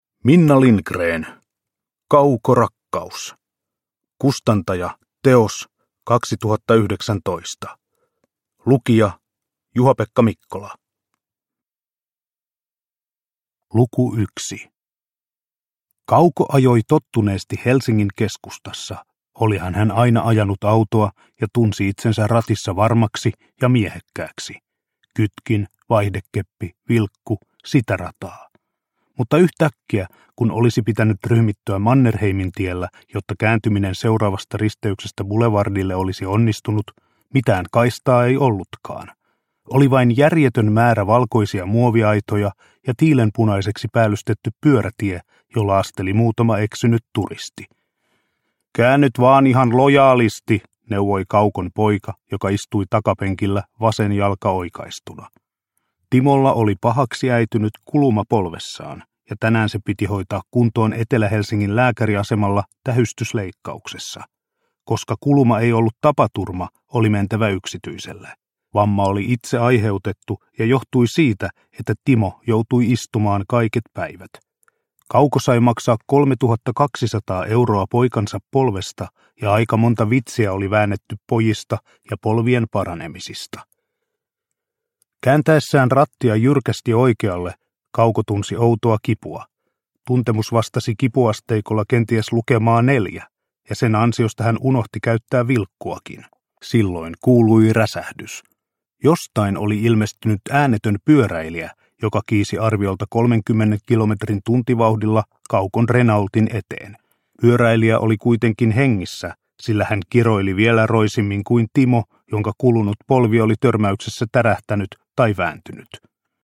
Kaukorakkaus – Ljudbok – Laddas ner